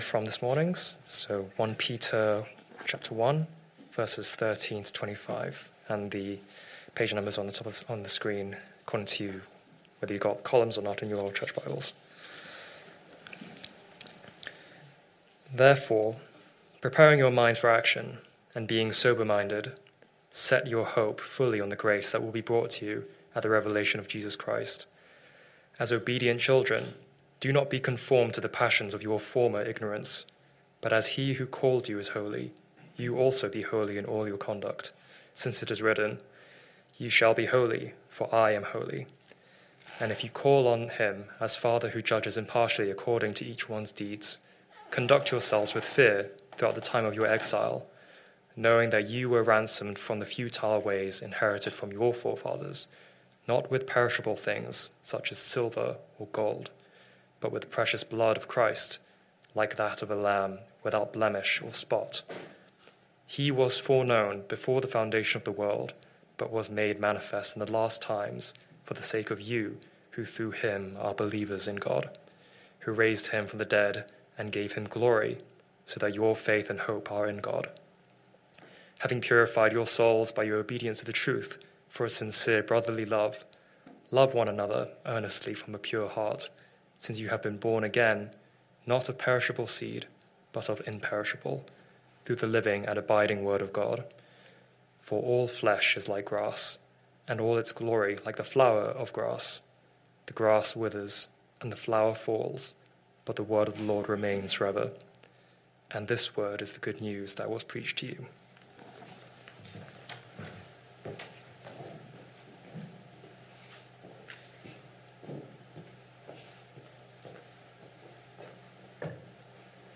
Message
Sunday AM Service Sunday 11th January 2026 Speaker